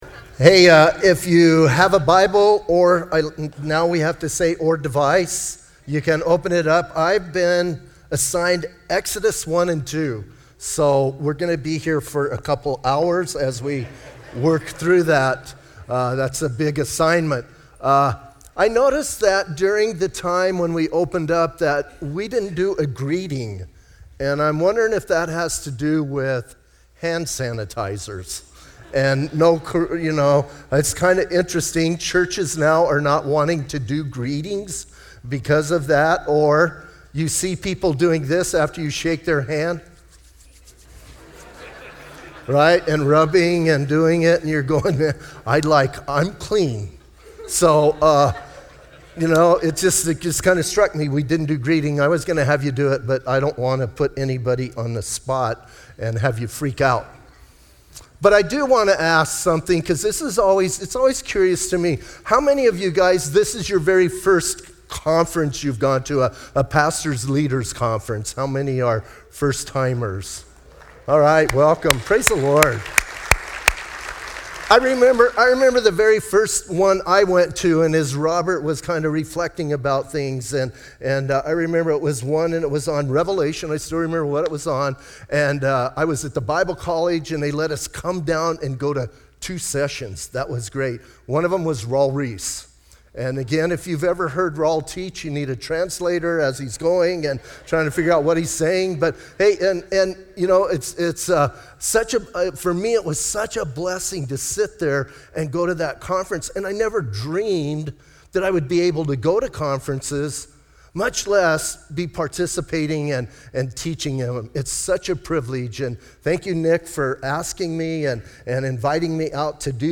Southwest Pastors and Leaders Conference 2020